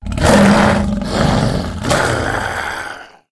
attack_hit_1.ogg